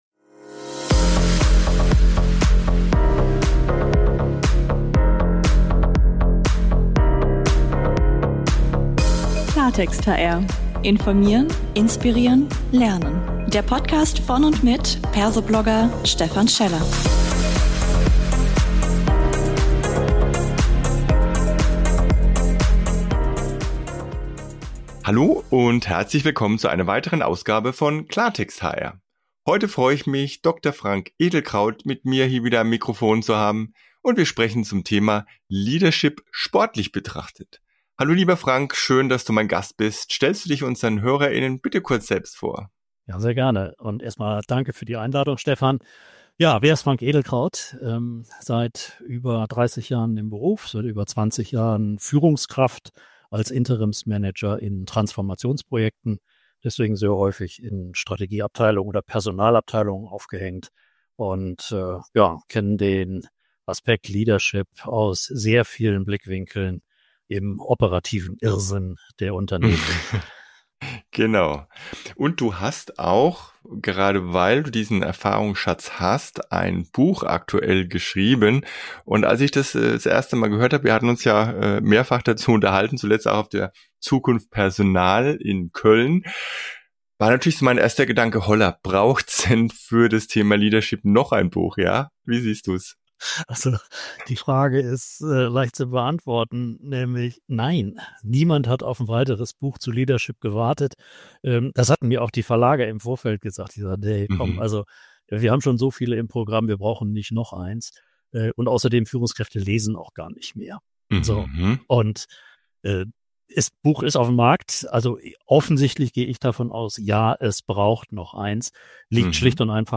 Ein spannender Talk als 15-Minuten-Impuls.